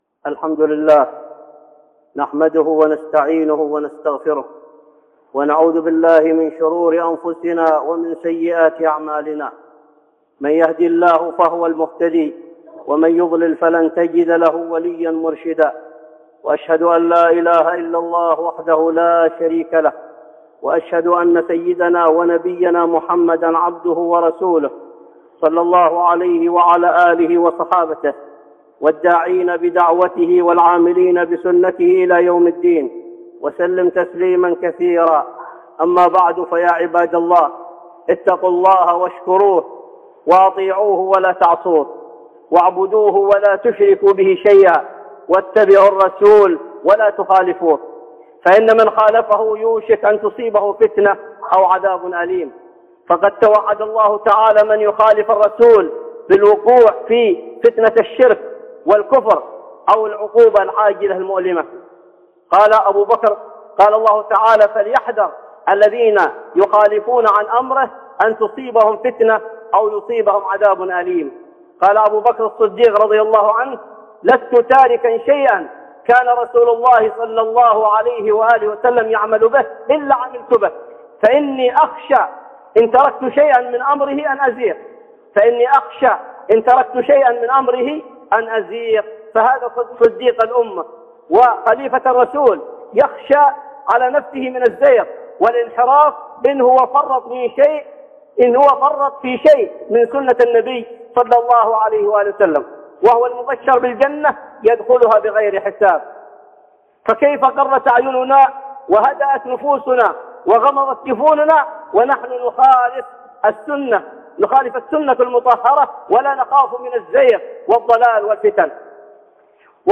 (خطبة جمعة) إن شانئك هو الأبتر